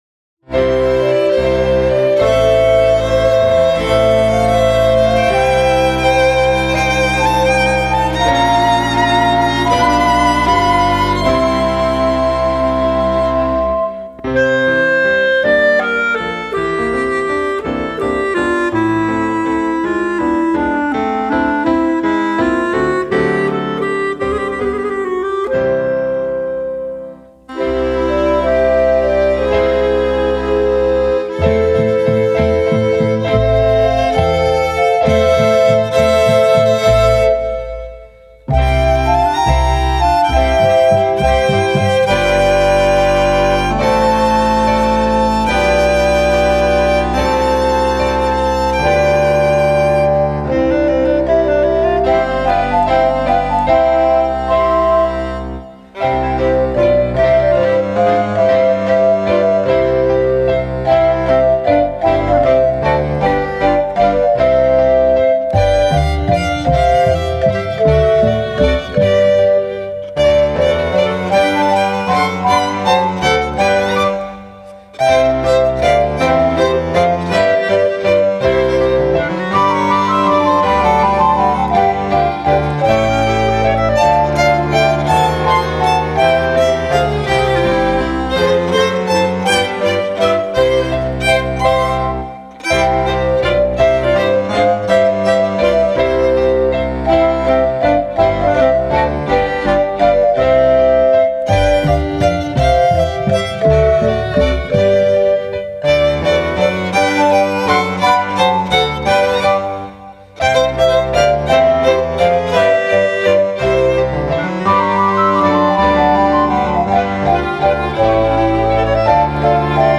818   07:03:00   Faixa:     Valsa